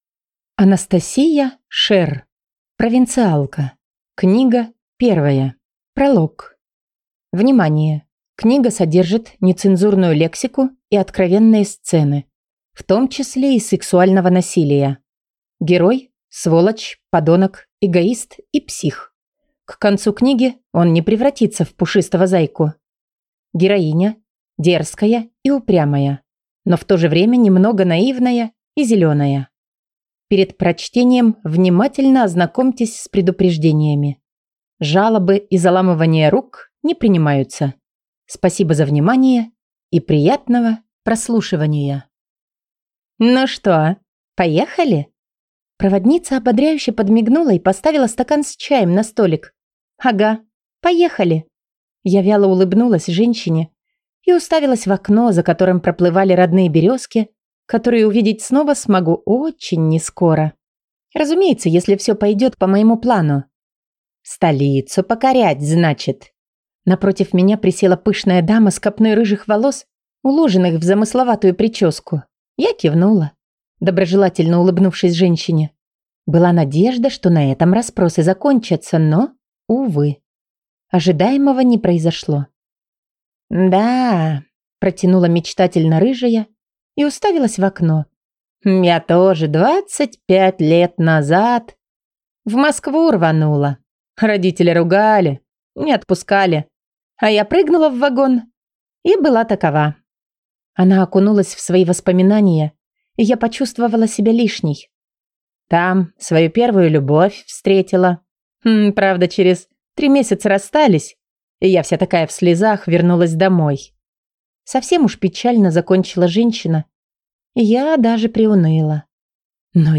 Аудиокнига Провинциалка. Книга первая | Библиотека аудиокниг
Прослушать и бесплатно скачать фрагмент аудиокниги